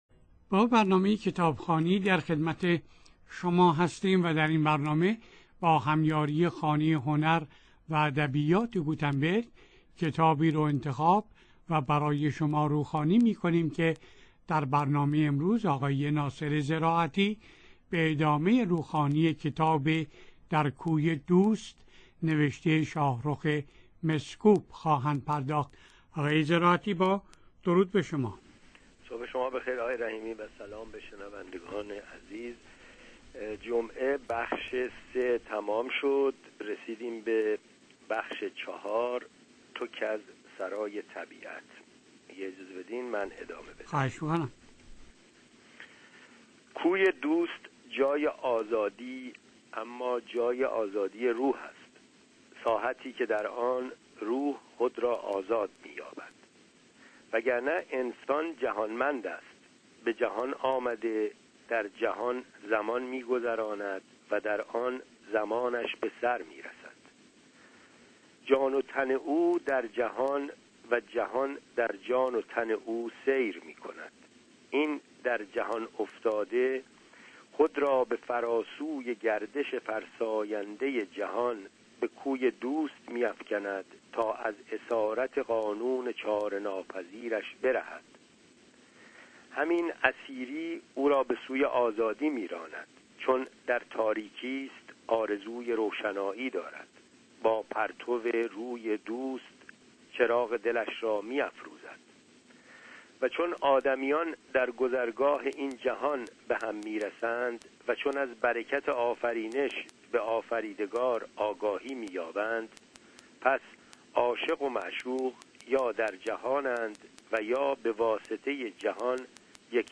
با هم این کتاب را می شنویم.